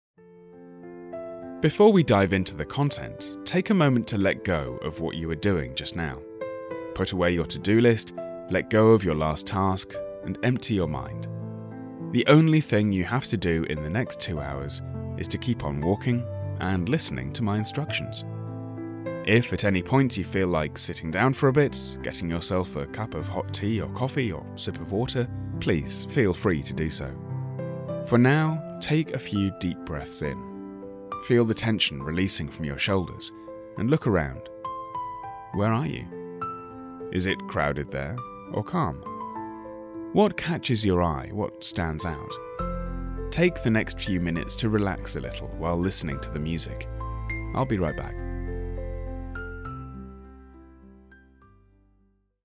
Genuino
Amistoso
Sofisticado